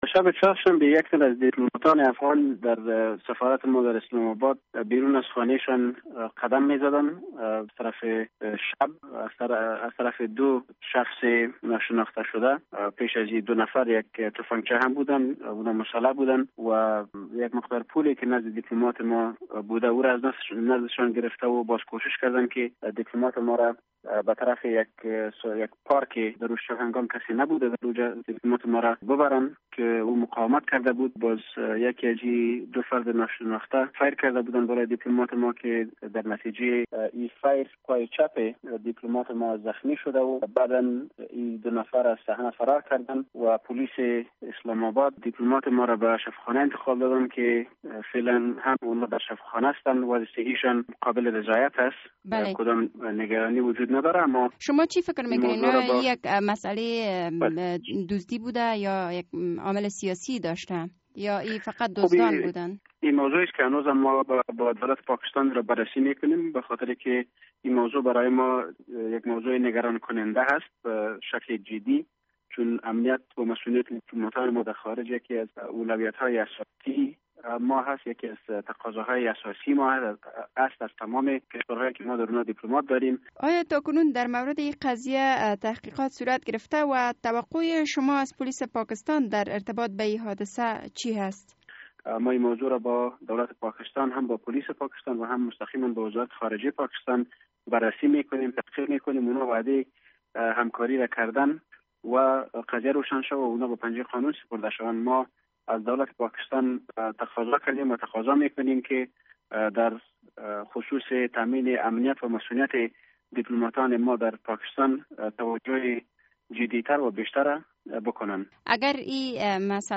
مصاحبه در مورد حمله بر جان یک دیپلومات افغان در پاکستان